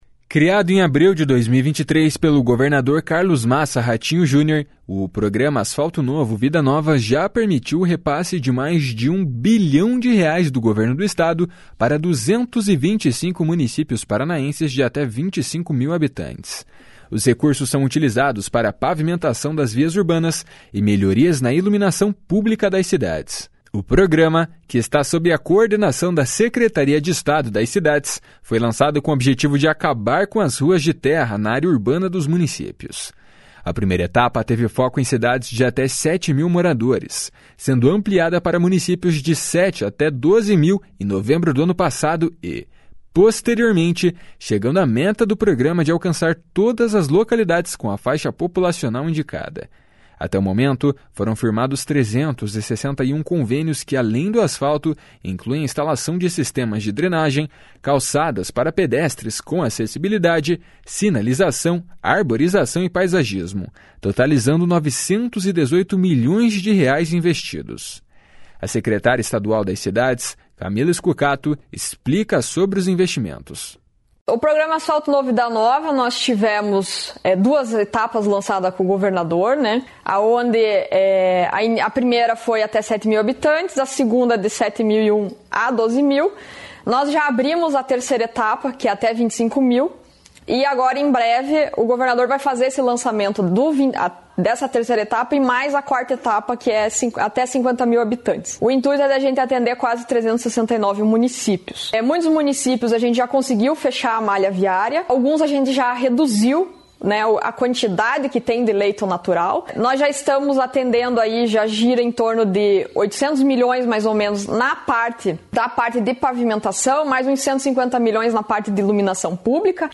A secretária estadual das Cidades, Camila Scucato, explica sobre os investimentos. // SONORA CAMILA SCUCATO //